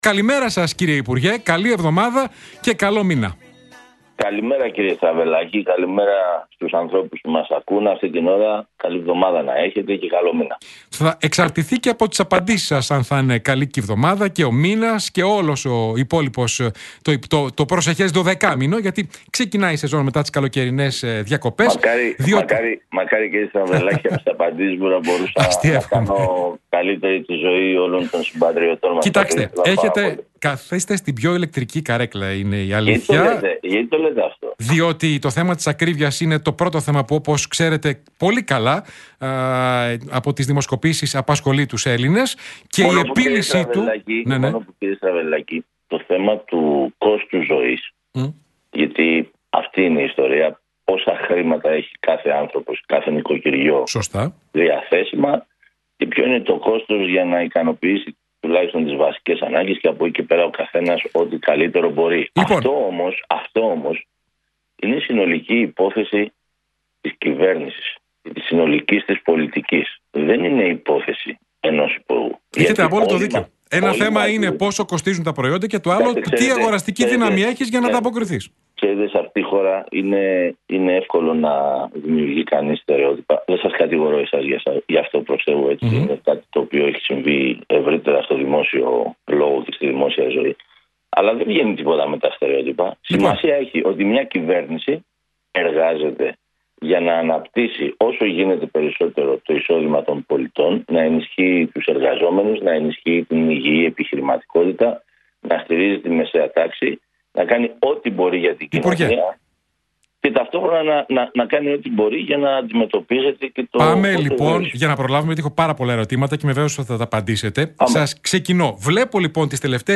Για το εάν η κυβέρνηση εξετάζει τον μηδενισμό του ΦΠΑ στο ελαιόλαδο ρωτήθηκε σήμερα ο υπουργός Ανάπτυξης, Τάκης Θεοδωρικάκος κατά τη συνέντευξη που